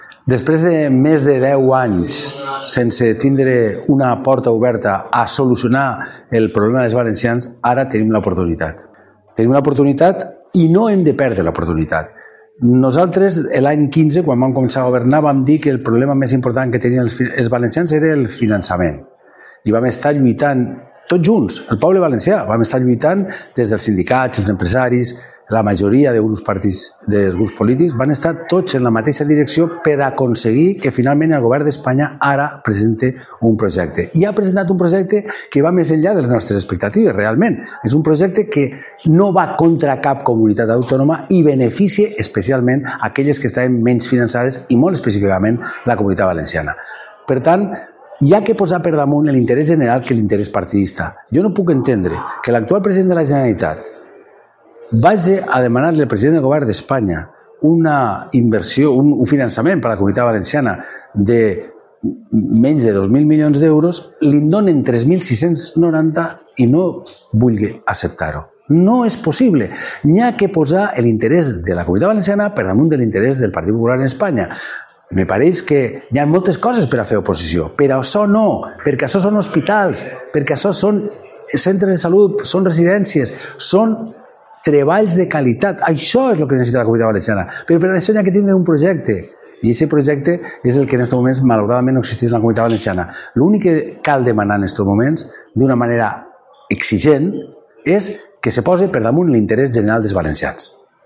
• Ximo Puig reivindica a Vinaròs l’oportunitat històrica del nou finançament per a blindar els serveis públics valencians.
L’expresident de la Generalitat i exsecretari general del PSPV-PSOE, Ximo Puig, ha defensat a Vinaròs “la necessitat inapel·lable d’aprofitar el nou projecte de finançament presentat pel Govern d’Espanya”.